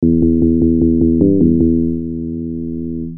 4.ベースに使えそうな音色
crfmbass.mp3